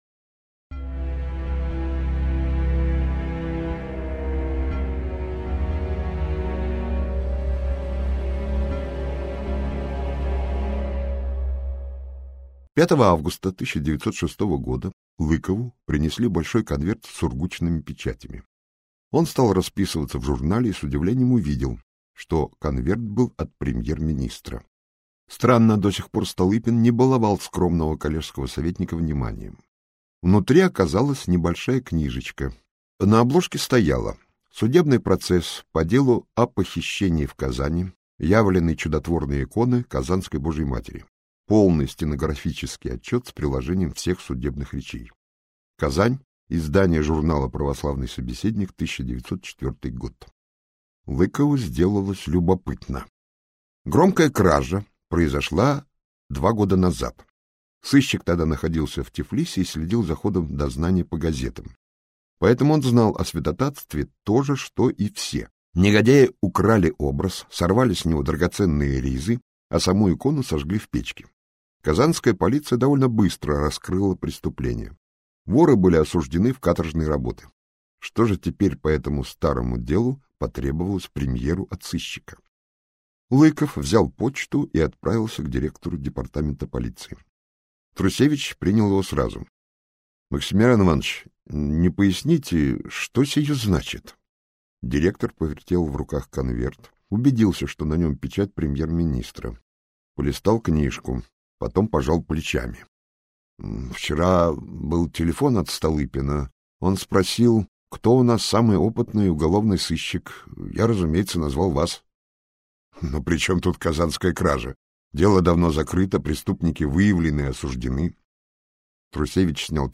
Аудиокнига По остывшим следам - купить, скачать и слушать онлайн | КнигоПоиск